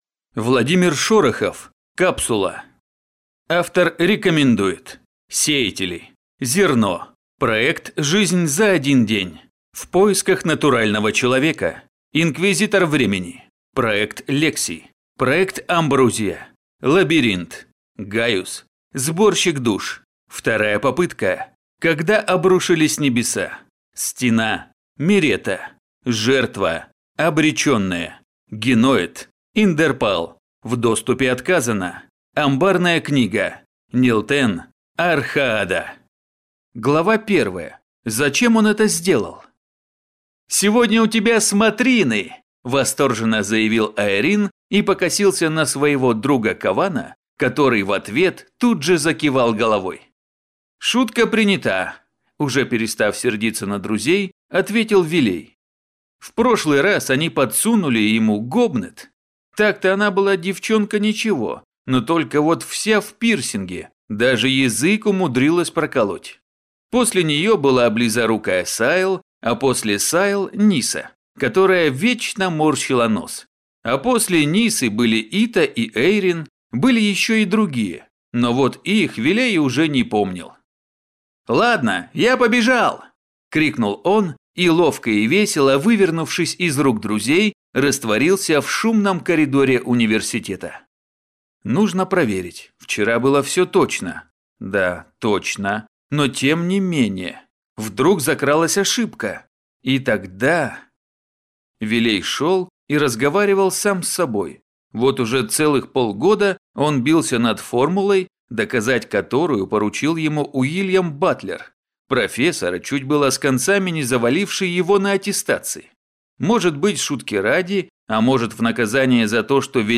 Аудиокнига Капсула | Библиотека аудиокниг